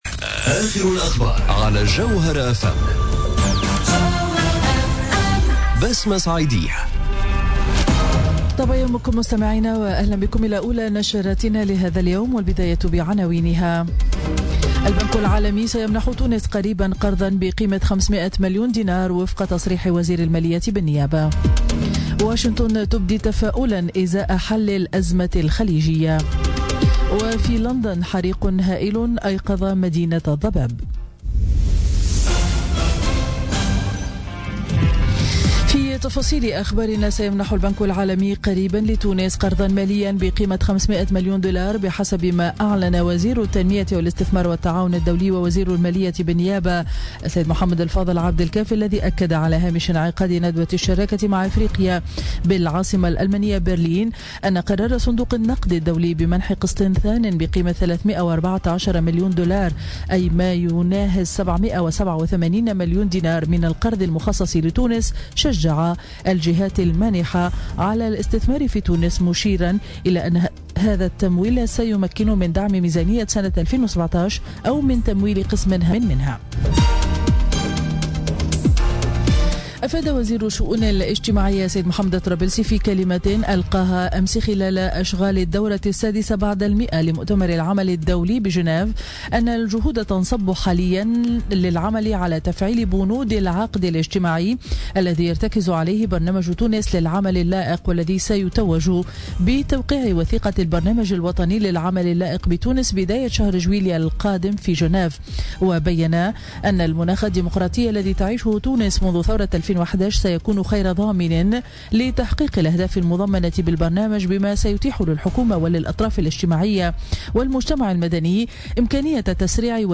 نشرة أخبار السابعة صباحا ليوم الاربعاء 14 جوان 2017